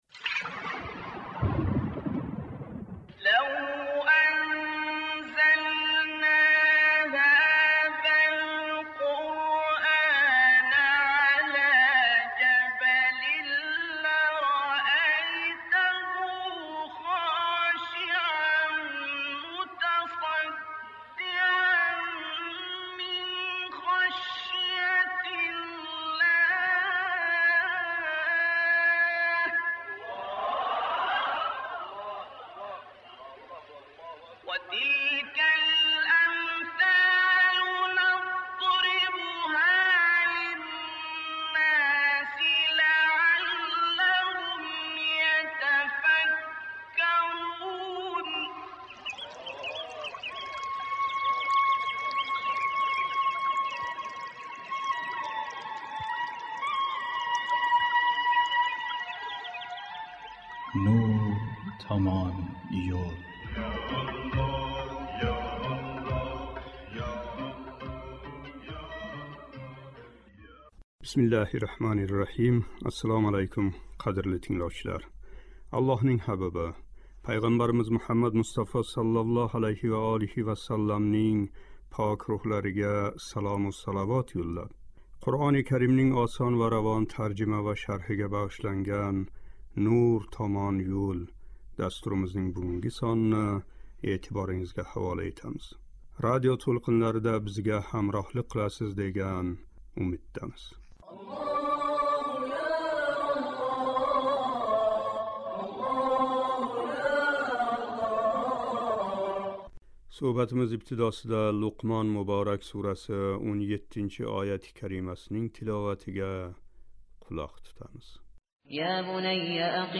"Луқмон " муборак сураси 17-19-ояти карималарининг шарҳи. Суҳбатимиз ибтидосида «Луқмон " муборак сураси 17-ояти каримасининг тиловатига қулоқ тутамиз :